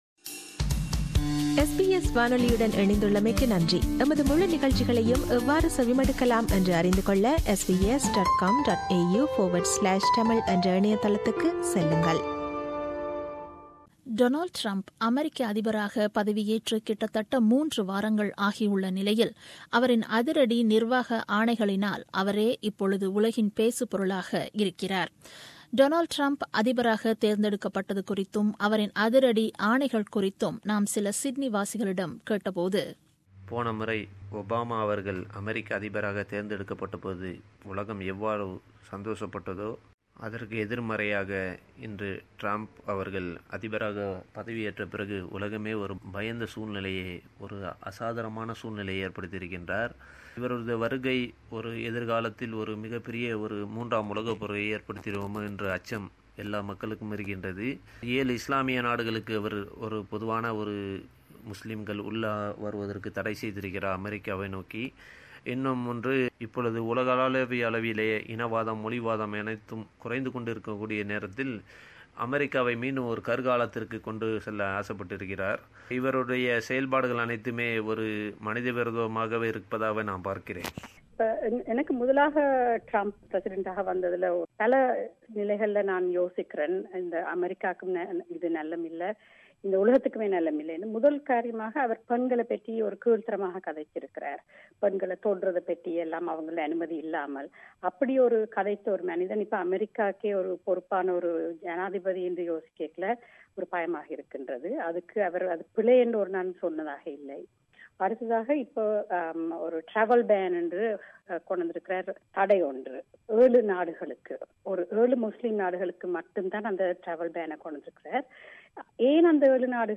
VoxPop : Sydney people about Donald Trump